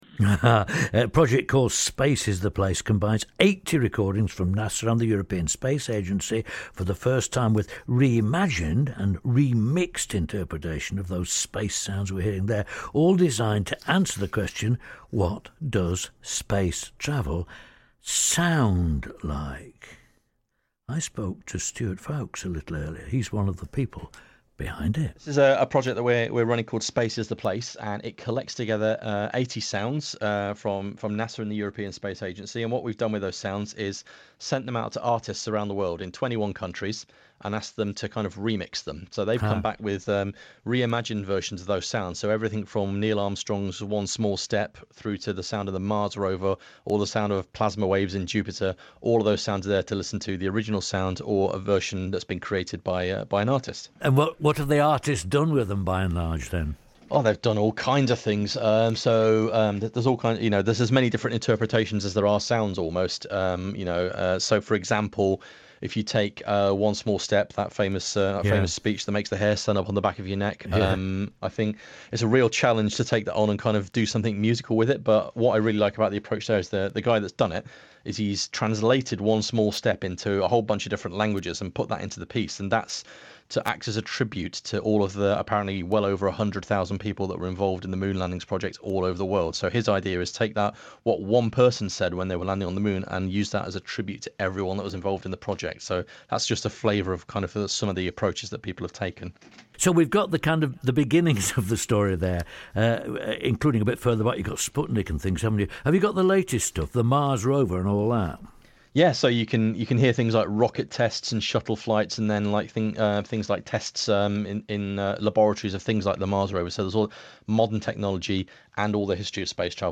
Interview about our Space is the Place project on BBC Radio Sheffield, 2 July 2019.